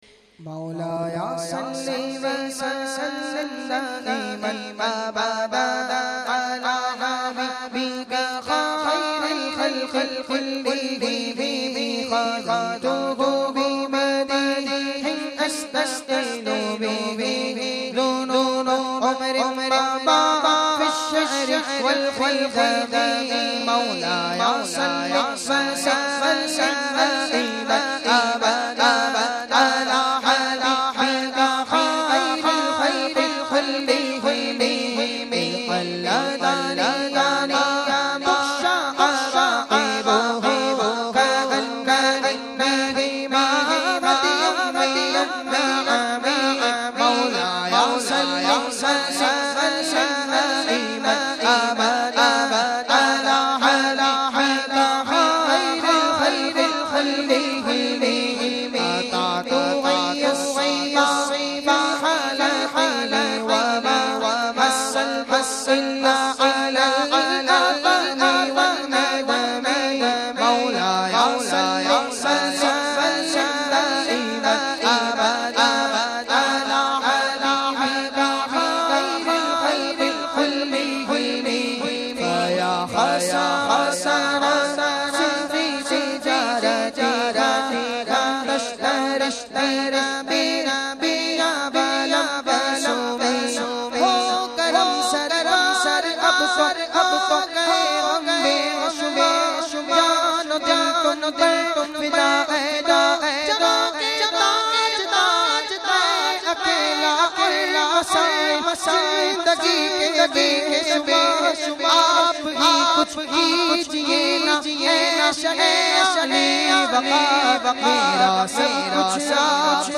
recited by famous Naat Khawan of Pakistan